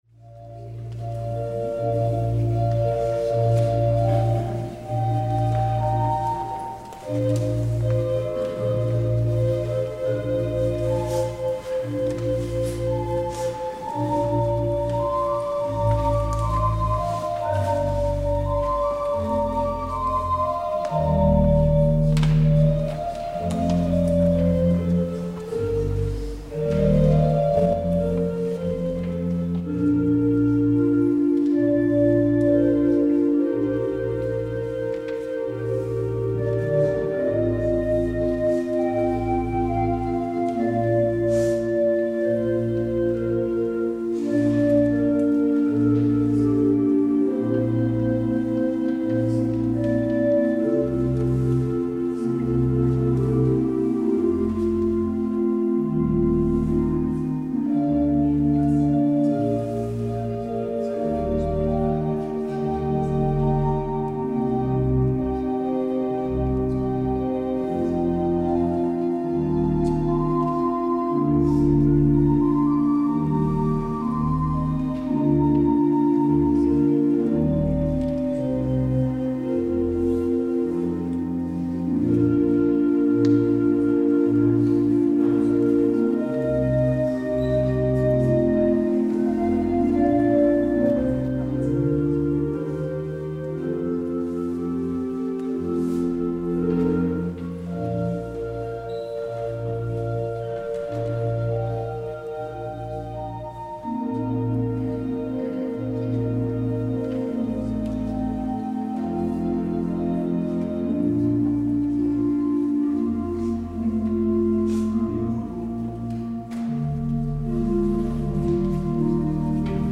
 Beluister deze kerkdienst hier: Alle-Dag-Kerk 19 februari 2025 Alle-Dag-Kerk https
Het openingslied is: Psalm 43: 1 en 3.
Het slotlied is: Gezang 449: 1, 3, 4 en 5.